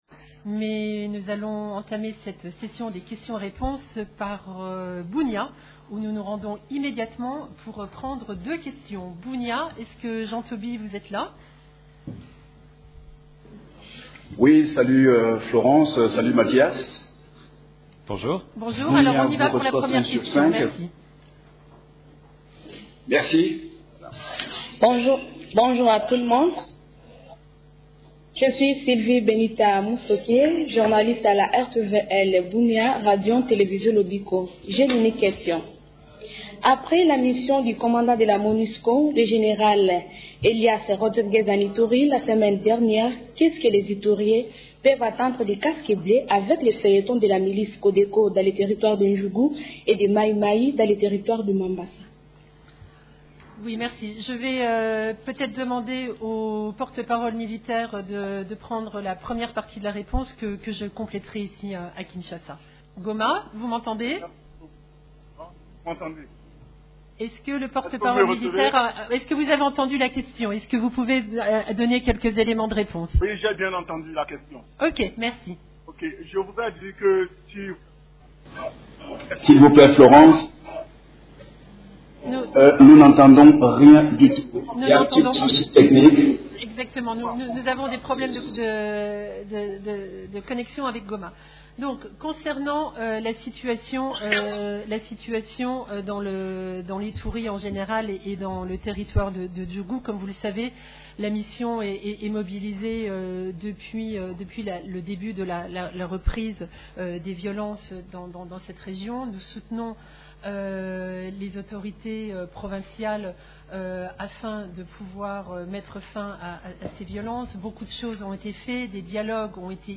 Conférence de presse de l'ONU à Kinshasa du mercredi 16 octobre 2019